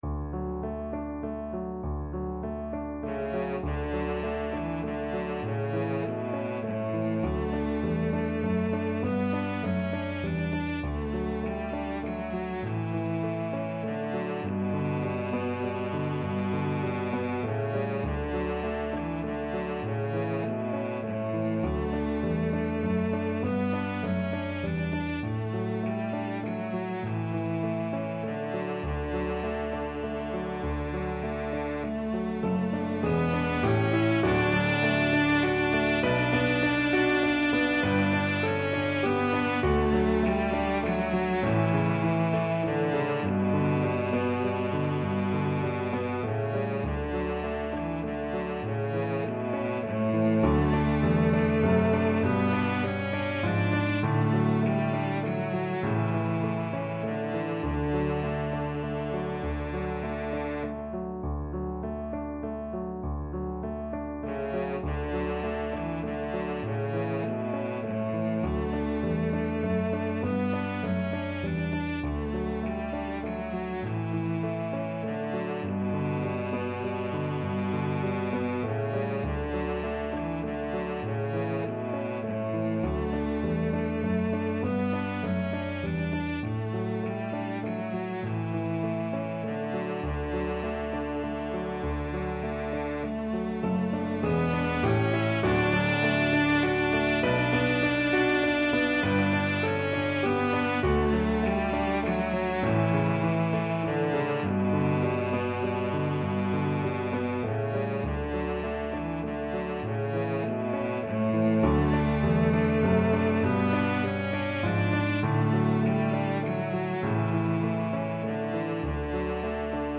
Traditional Trad. The Gentle Maiden (Irish Folk Song) Cello version
Cello
D major (Sounding Pitch) (View more D major Music for Cello )
6/8 (View more 6/8 Music)
Gently flowing =100
Traditional (View more Traditional Cello Music)